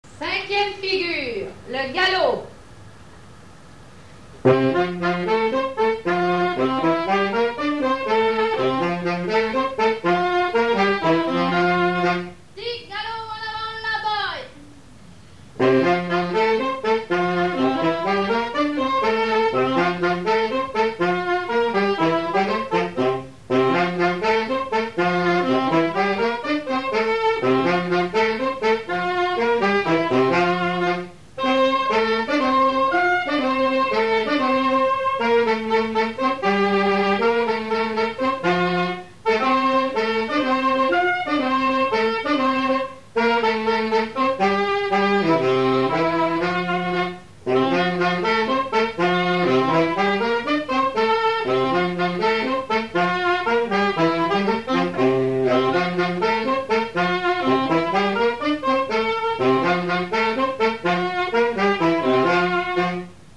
5ème figure du quadrille
Résumé instrumental
danse : quadrille : galop
Pièce musicale inédite